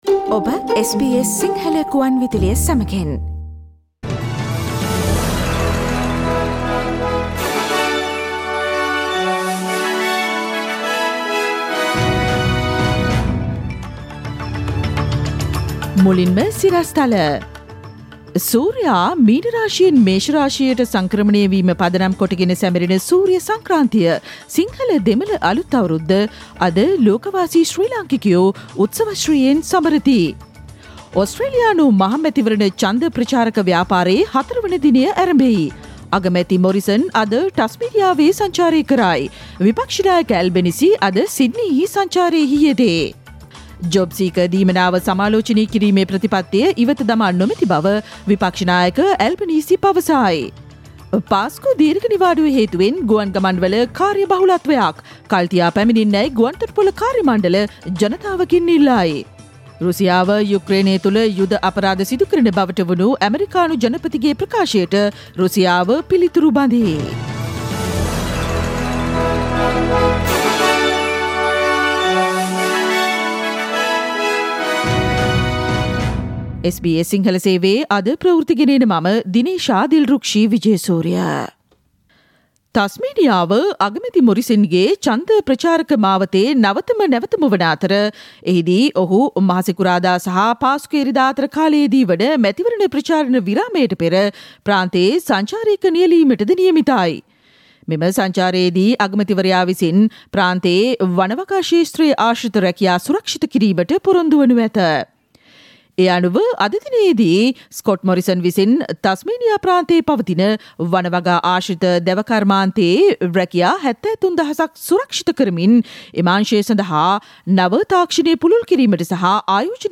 Click on the speaker icon on the image above to listen to the SBS Sinhala Radio news bulletin on Thursday 14 April 2022.